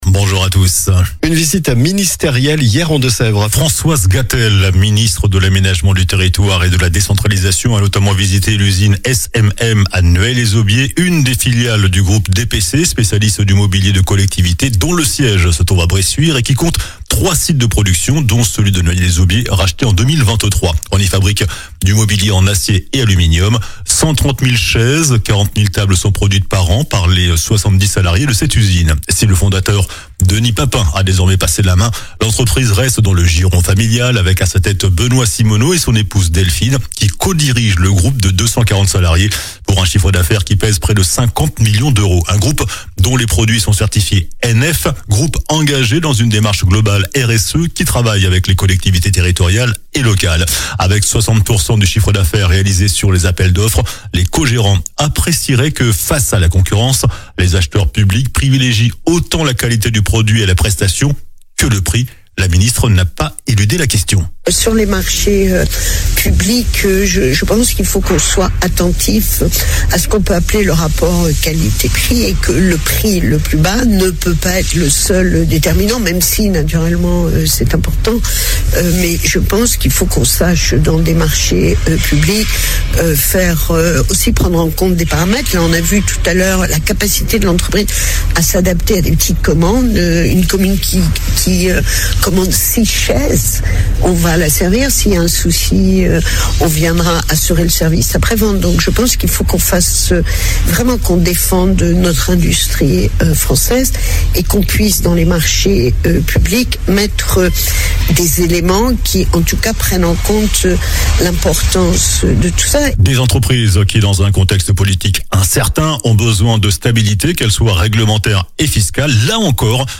COLLINES LA RADIO : Réécoutez les flash infos et les différentes chroniques de votre radio⬦
JOURNAL DU SAMEDI 07 FEVRIER